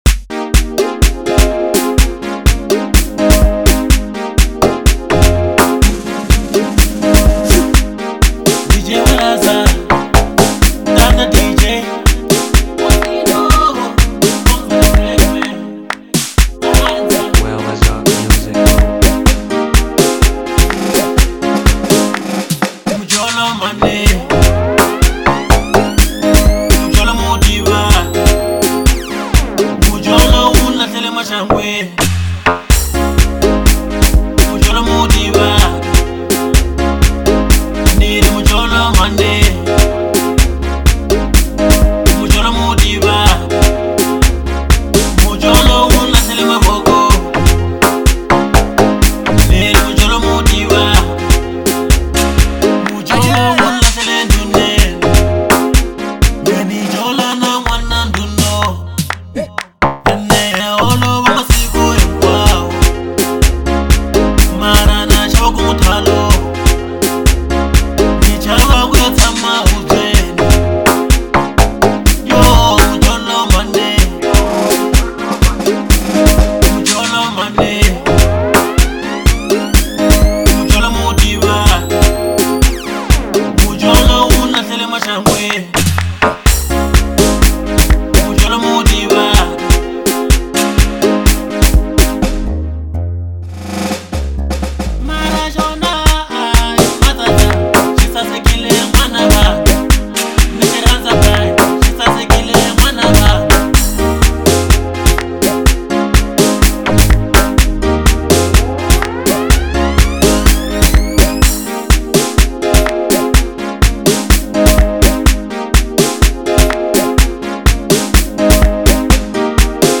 03:45 Genre : Local House Size